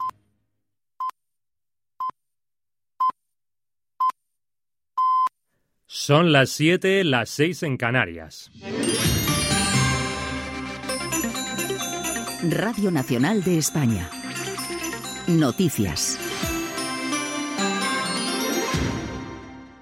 Senyals horaris, hora (19:00) i careta del butlletí. Sortida del butlletí. Indicatiu de l'emissora.
Informatiu